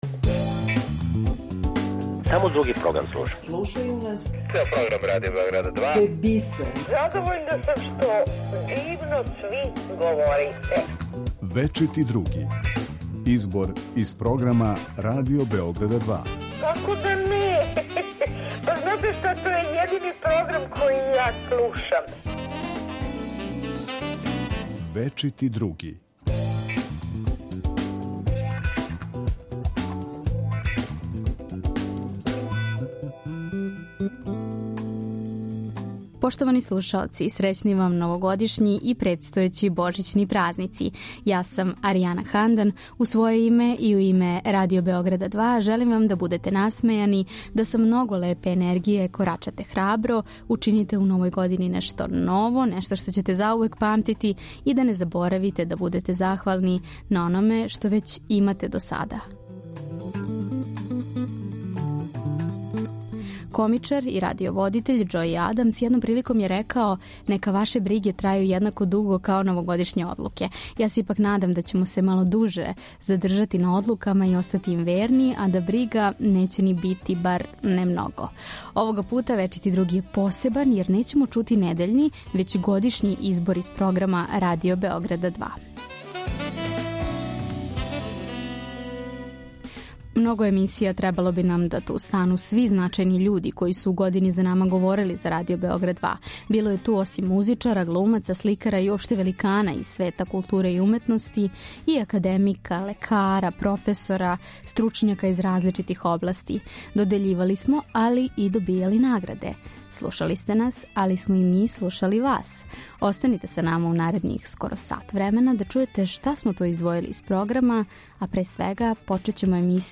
(реприза)